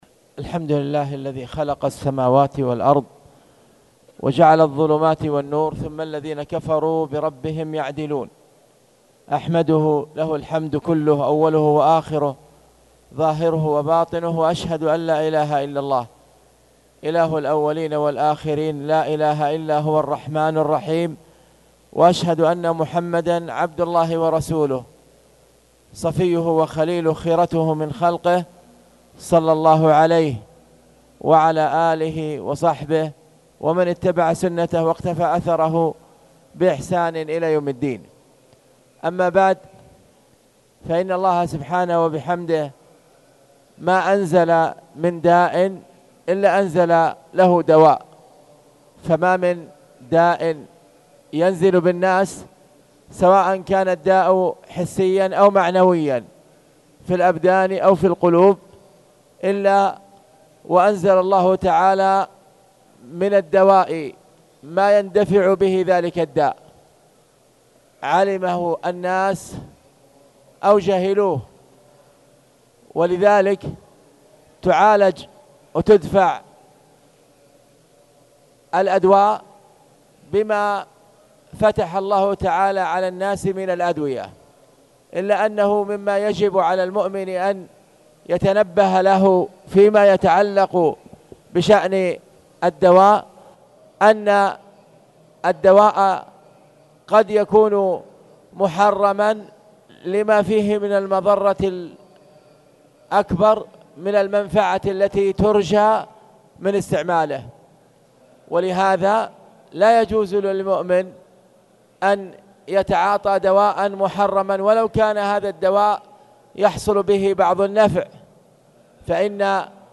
تاريخ النشر ٧ رجب ١٤٣٨ هـ المكان: المسجد الحرام الشيخ: خالد بن عبدالله المصلح خالد بن عبدالله المصلح باب ما جاء في النشرة